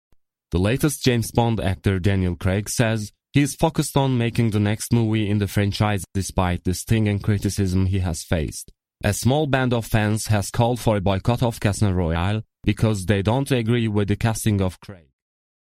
Male | Teenage,Male | Young Adult in genders and Presentations